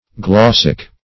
Glossic \Glos"sic\ (gl[o^]s"s[i^]k), n. [L. glossa a word